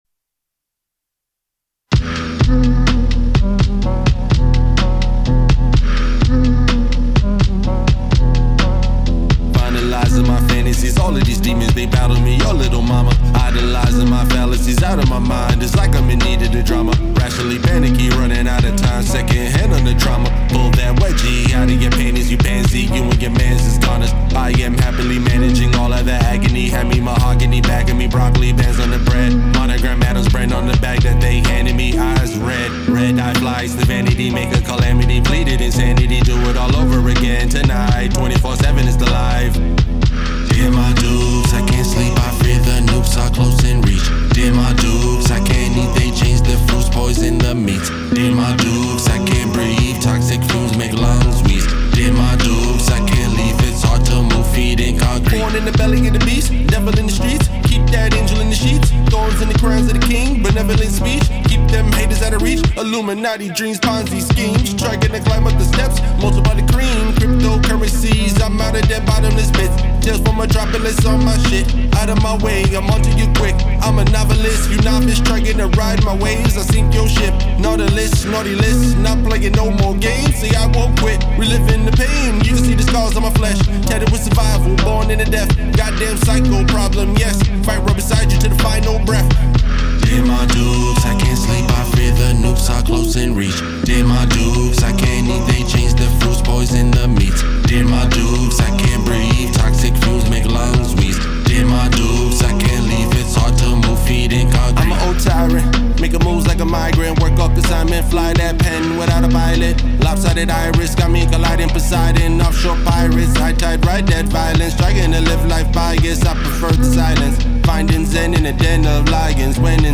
a nice bounce